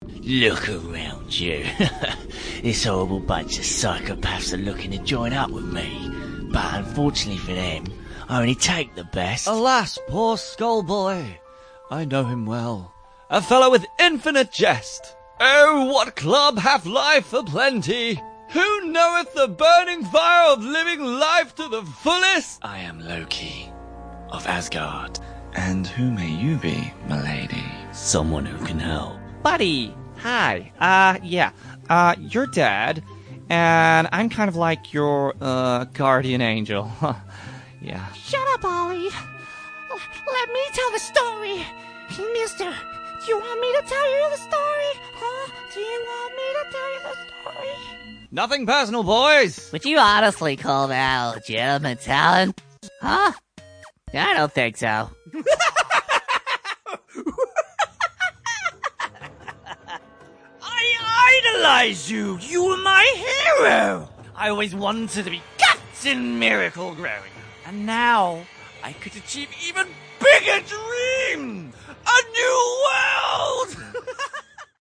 Male
Professional recording studio, Rode NT1A, Reaper, Izotope RX & more.
Video Games
Young, Upbeat & Characterful